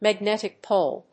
アクセントmagnétic póle